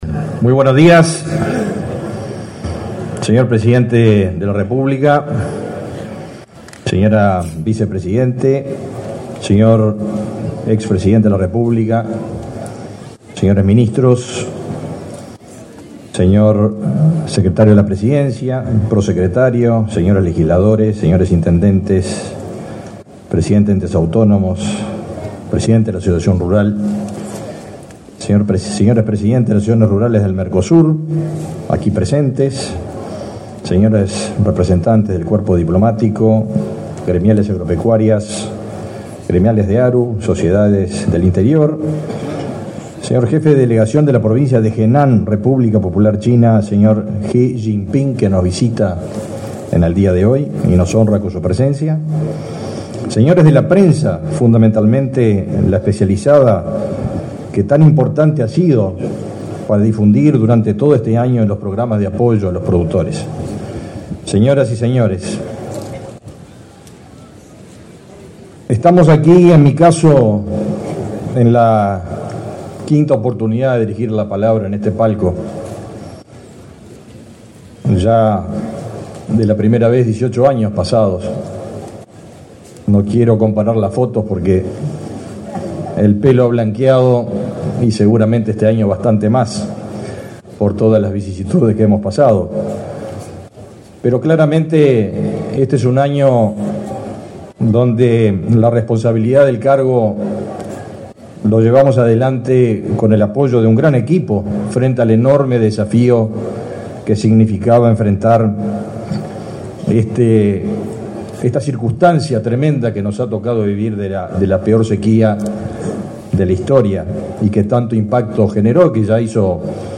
Palabras del titular del MGAP, Fernando Mattos
En el acto realizó declaraciones el titular del Ministerio de Ganadería, Agricultura y Pesca (MGAP), Fernando Mattos.